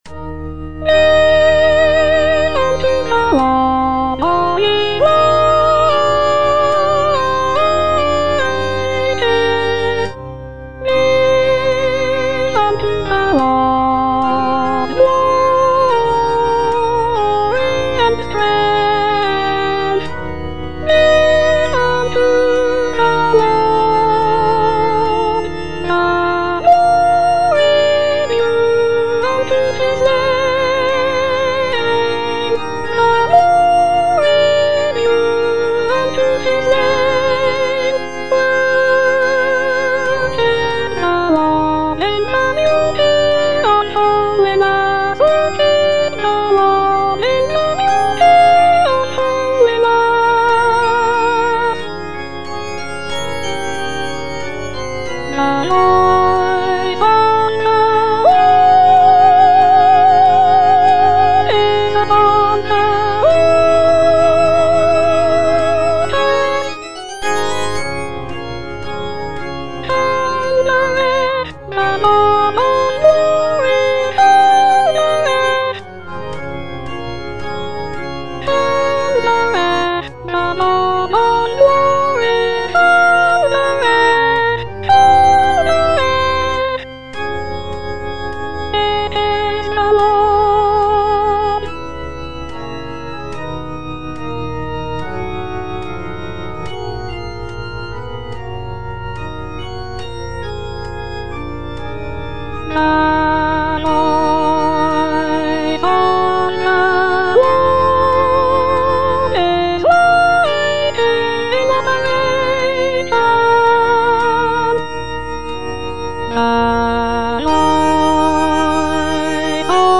E. ELGAR - GIVE UNTO THE LORD Soprano I (Voice with metronome) Ads stop: auto-stop
"Give unto the Lord" is a sacred choral work composed by Edward Elgar in 1914.